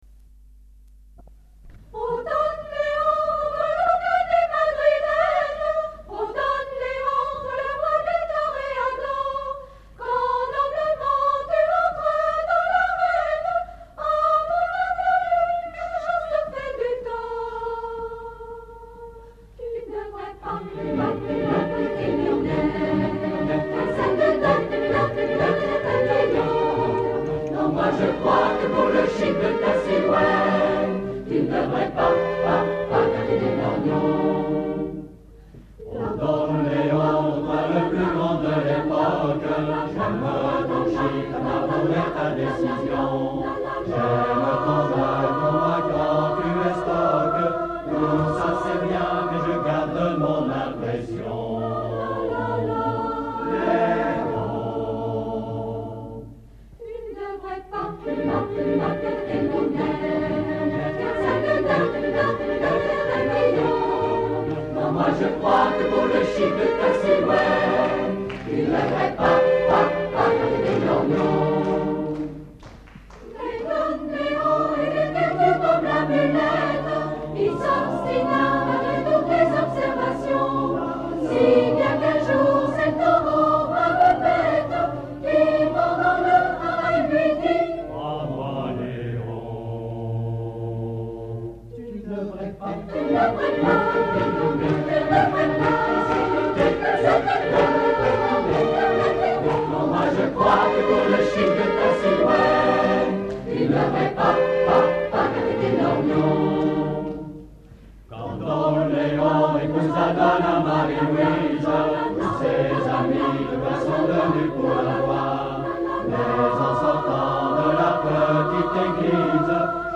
Concert  ACJ Macon CAC 20h30 27 Mai 1988 MACON